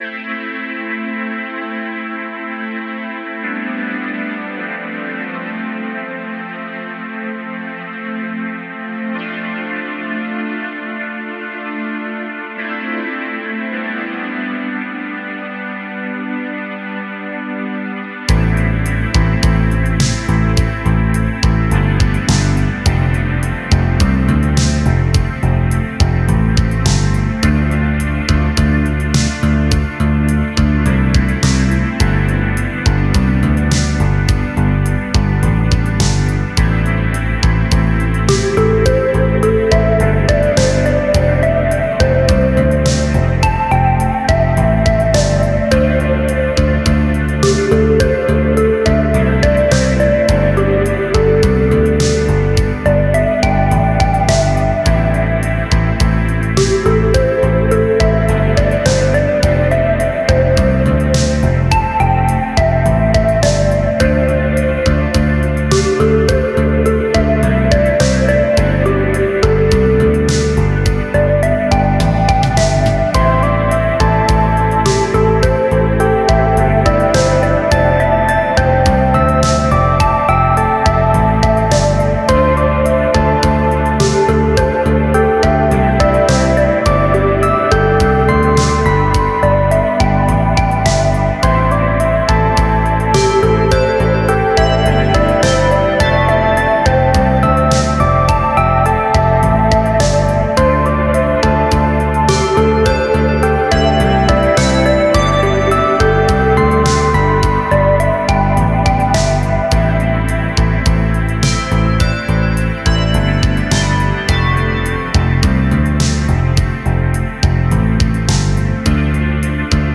Жанр: Synthwav